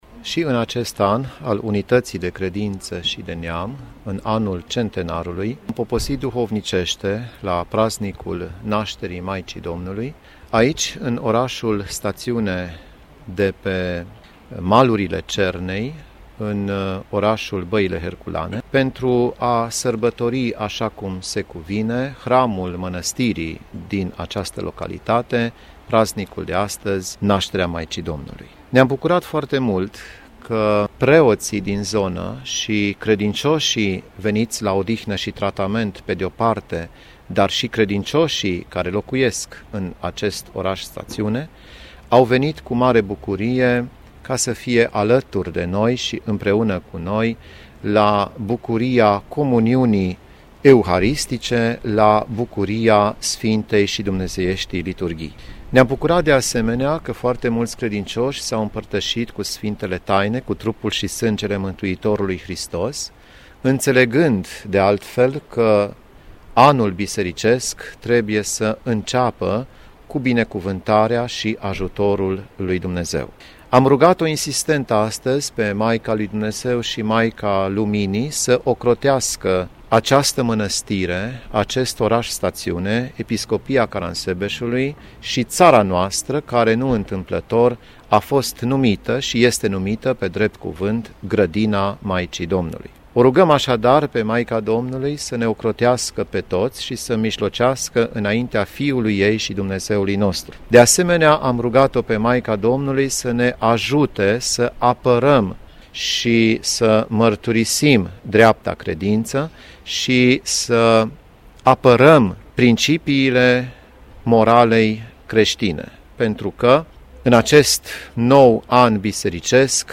La sfârșitul Slujbei Sfintei Liturghii, Prea Sfințitul Lucian ne-a declarat :
Prea-Sfintitul-Lucian.mp3